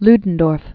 (ldn-dôrf), Erich Friedrich Wilhelm von 1865-1937.